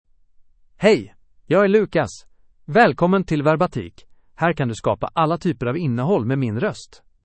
LucasMale Swedish AI voice
Lucas is a male AI voice for Swedish (Sweden).
Voice sample
Listen to Lucas's male Swedish voice.
Male
Lucas delivers clear pronunciation with authentic Sweden Swedish intonation, making your content sound professionally produced.